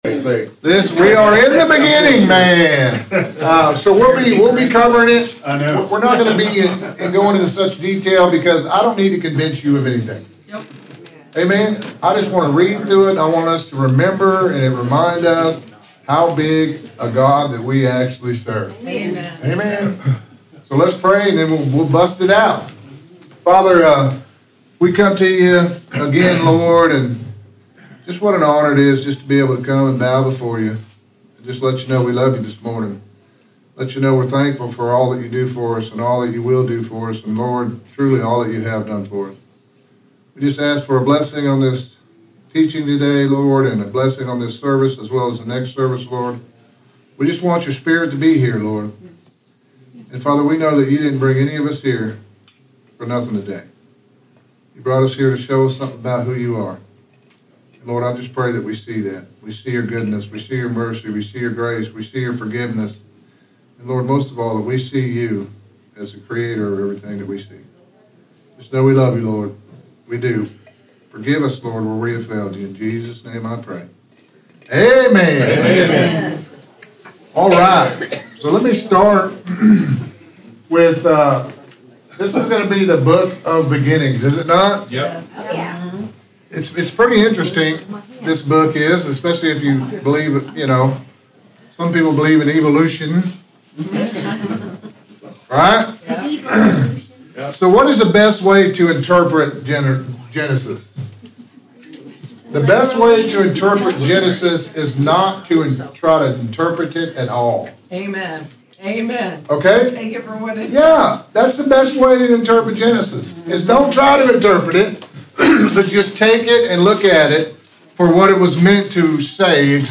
Interactive Bible Study
Sermon Audio